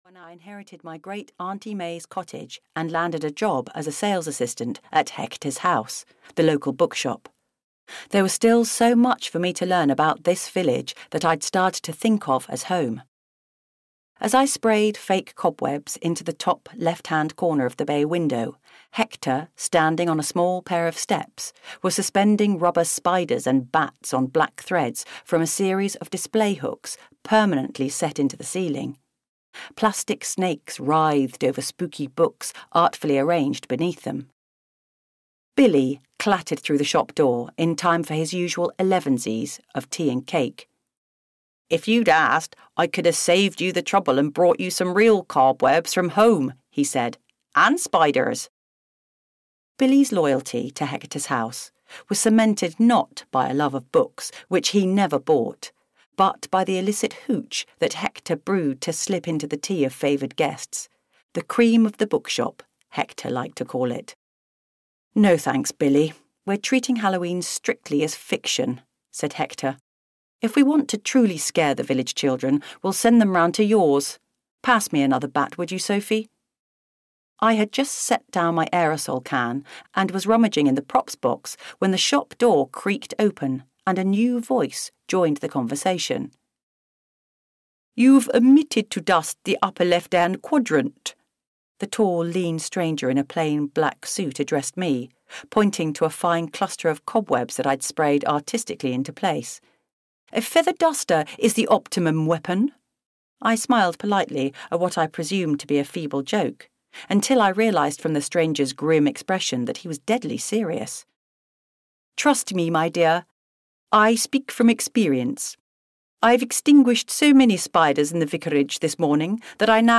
Murder at the Vicarage (EN) audiokniha
Ukázka z knihy